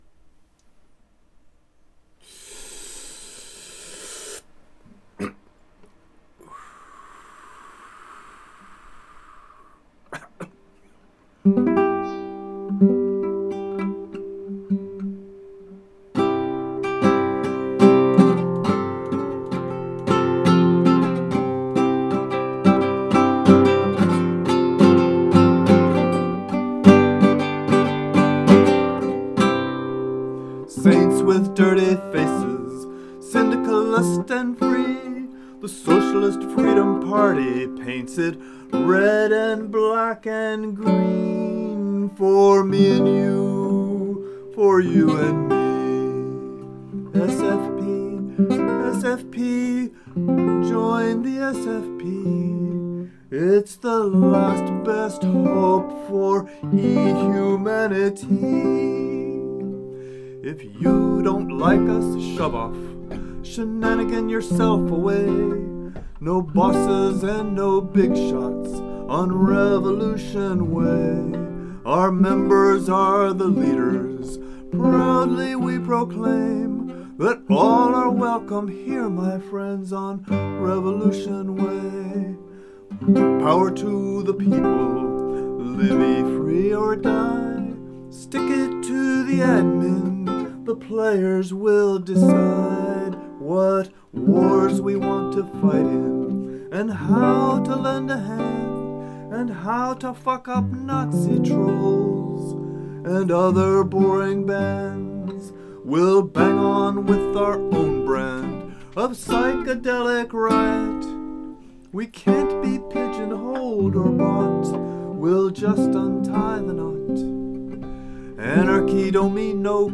Silly song I made, impersonating Woody Guthrie for gaming buddies in the 'eRepublik Socialist Freedom Party', which of course is "the last best hope for e-humanity".